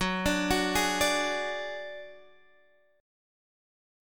F#sus2#5 Chord